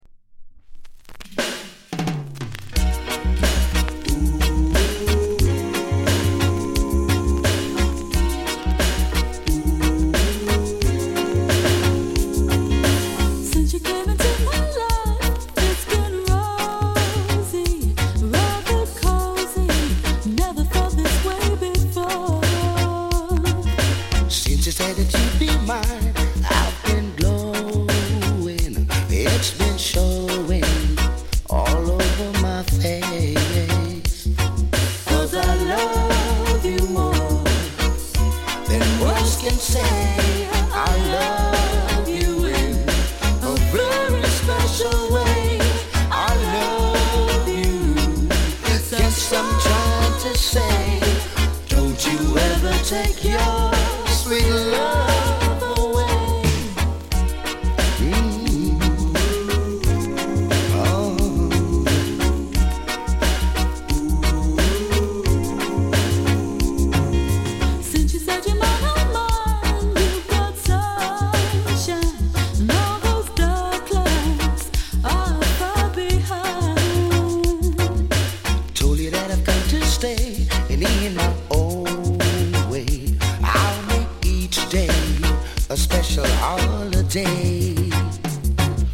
軽いチリ 乗りますが、気になるレベルではありません。
類別 雷鬼